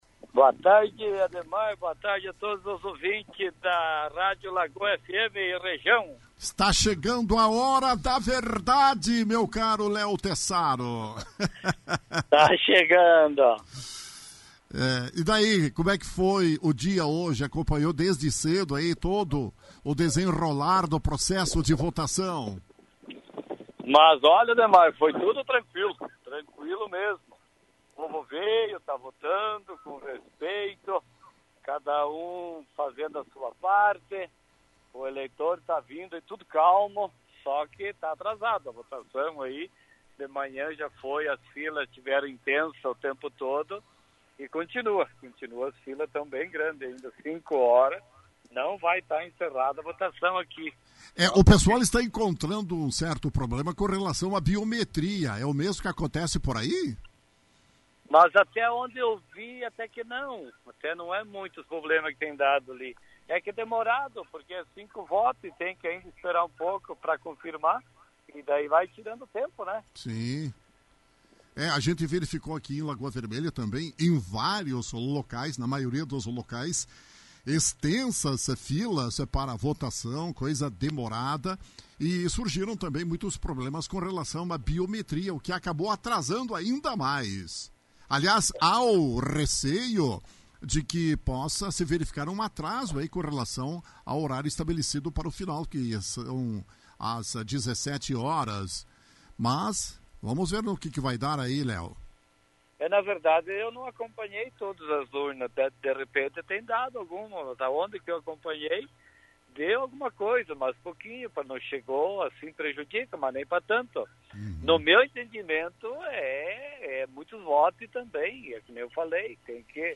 Léo Tessaro, prefeito do município de Caseiros, também se manifestou aos nossos microfones. Destacou que na cidade de Caseiros também ocorre uma certa demora na hora da votação, explicou ainda que com certeza irá atrasar o término. Aproveitou e fez uma avaliação sobre as eleições.